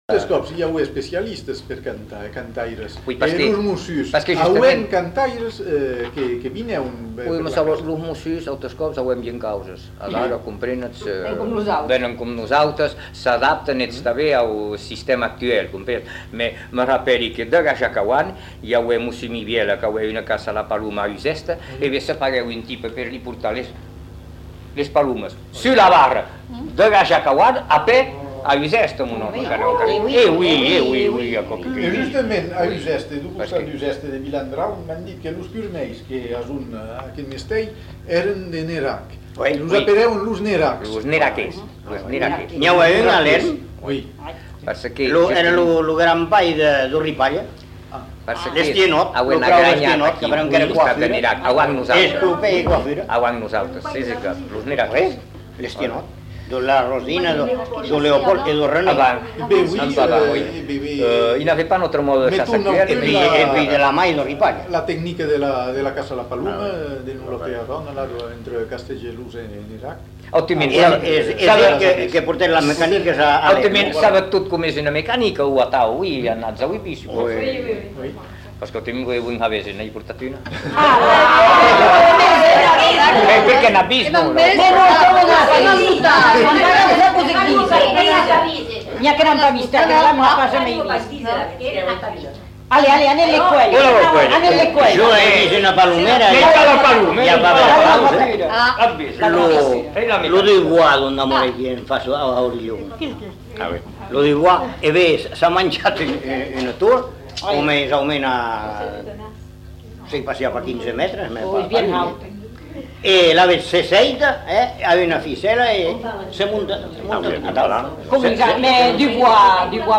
Lieu : Bazas
Genre : témoignage thématique
[enquêtes sonores]